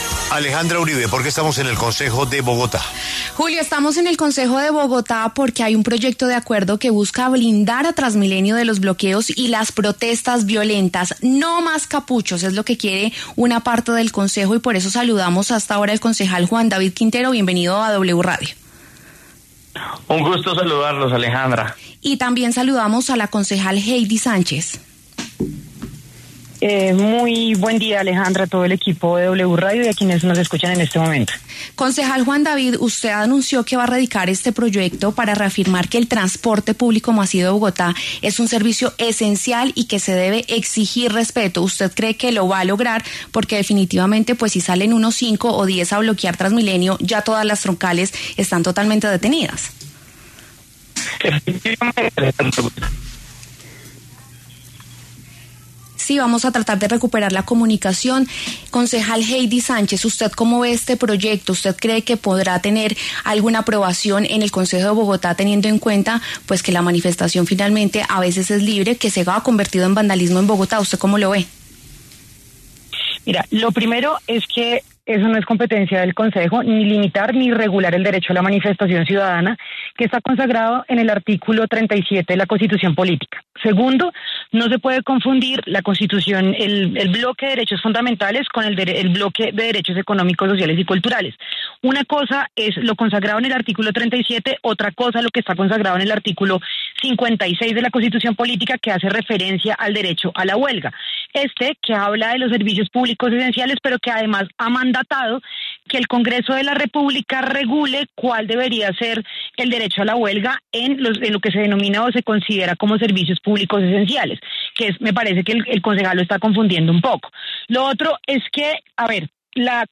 En los micrófonos de W Radio, los concejales Juan David Quintero y Heidy Sánchez del Pacto Histórico debatieron sobre el nuevo Proyecto de Acuerdo para reafirmar que el transporte público masivo de Bogotá es un servicio público esencial y exigir que la ciudad garantice su prestación ante bloqueos violentos.